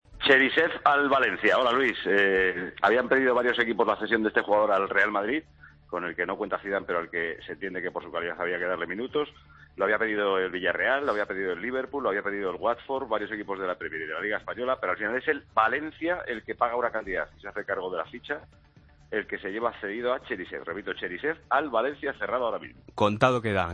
AUDIO: Paco González adelanta en el boletín de las 18:00 horas que Cheryshev se va cedido al Valencia